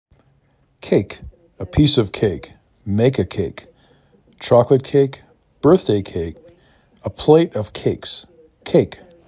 k A k
Local Voices
Wisconsin